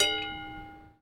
"Eindping" overwegbel laag, 1 seconde lang, 25,8 kB MP3 mono 48000 Hz.
eindping overweg.mp3